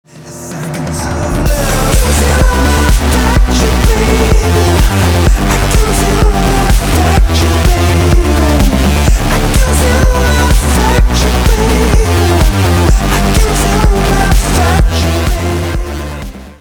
• Качество: 320, Stereo
спокойные